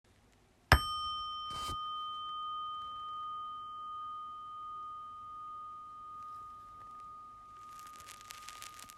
När man snurrar klubban runt skålen avges ljud som når oss, resonerar med vårt inre och hjälper oss att släppa taget.
Lyssna på vibrationerna från den 10 cm stora tibetanska skålen Chö-Pa